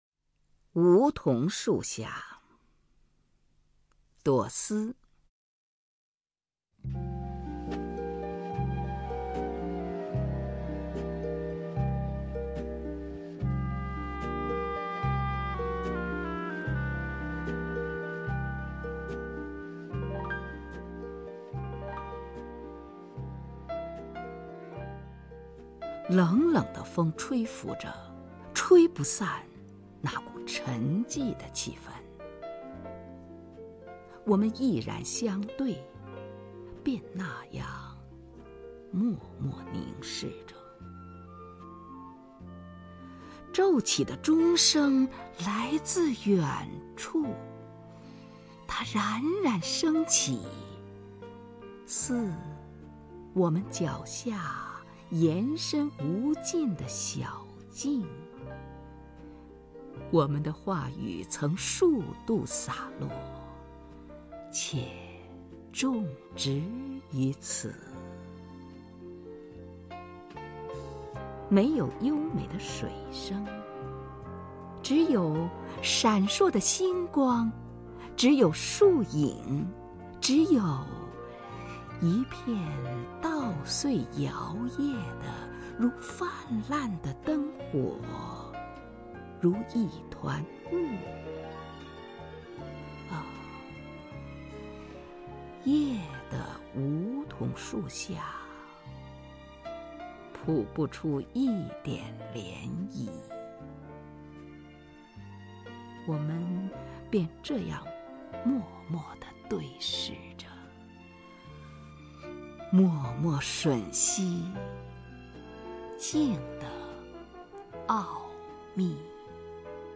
首页 视听 名家朗诵欣赏 虹云
虹云朗诵：《梧桐树下》(朵思)